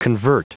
Prononciation du mot convert en anglais (fichier audio)
Prononciation du mot : convert